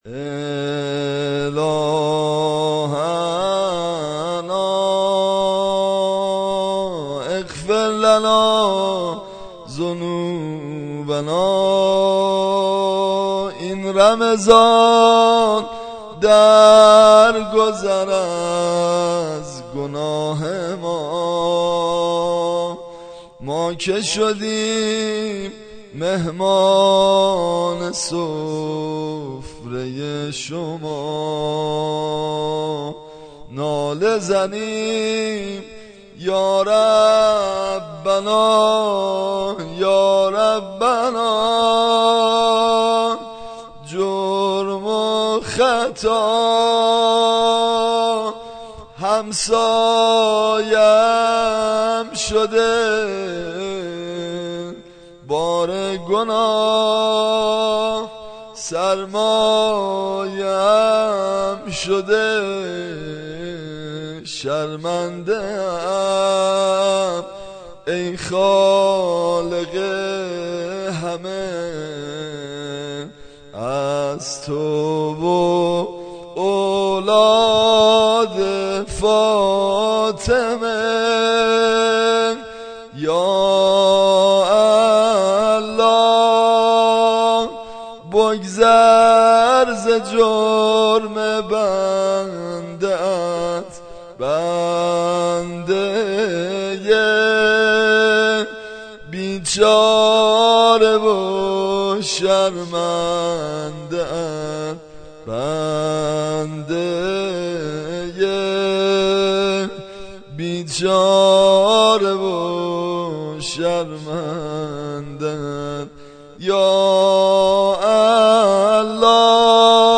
زمزمه ی مناجات و روضه